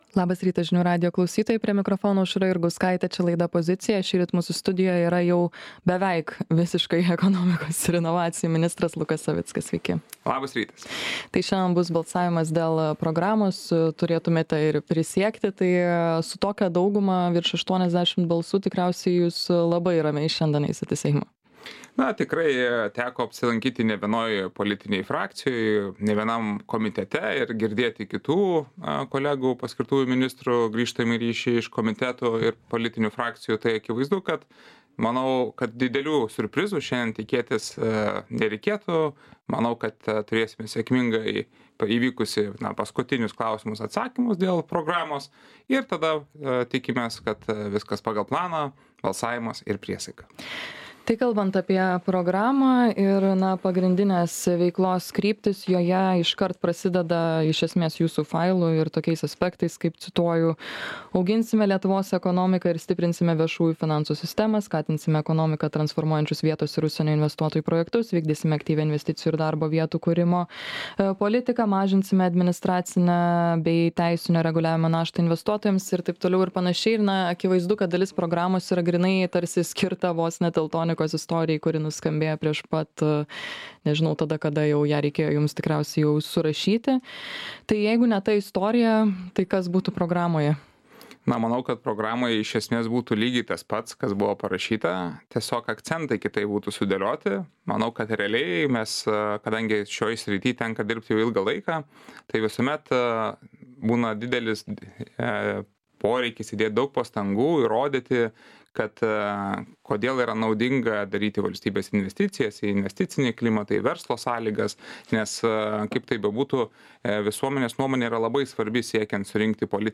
Studijoje – būsimas ekonomikos ir inovacijų ministras Lukas Savickas.